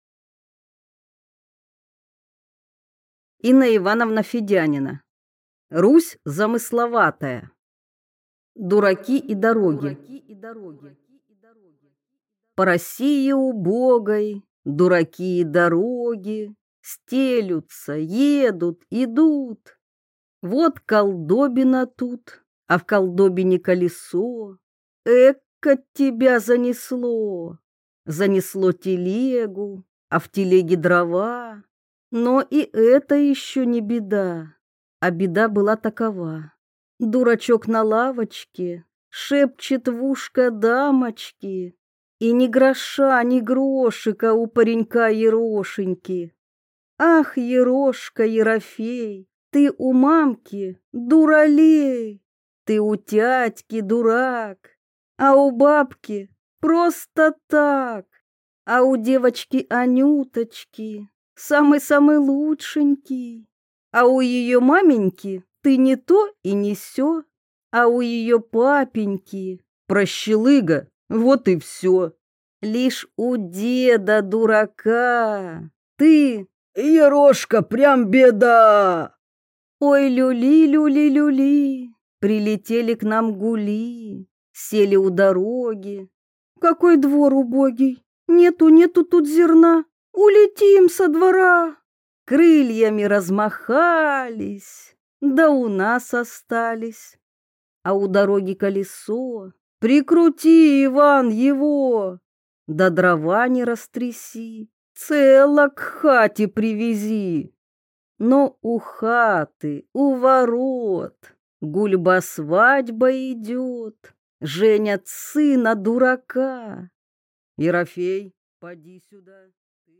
Аудиокнига Стихи: Русь замысловатая | Библиотека аудиокниг